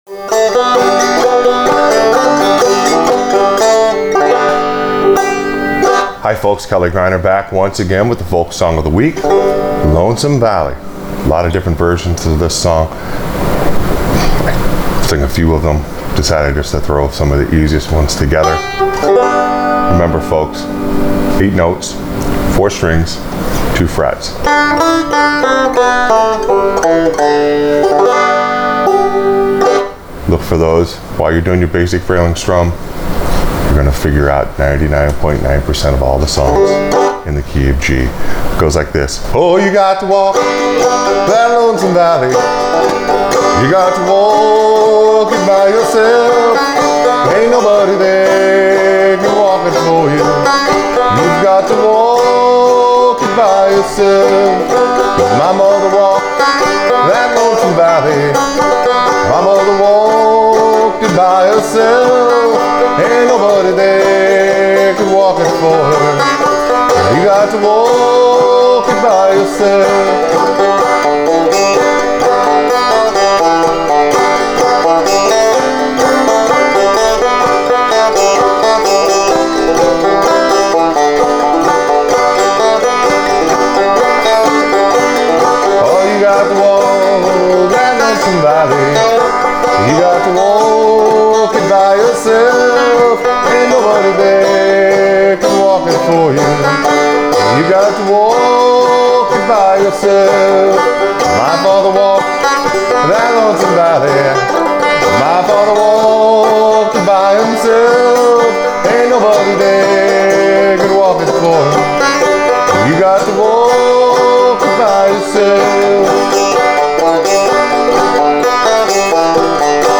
Folk Song Of The Week – Lonesome Valley on Frailing Banjo
Always remember on Frailing Banjo in the Key of G, you can find 99.9% of the melody notes on the first two frets on the four strings. 8 notes, 2 frets, and 4 strings.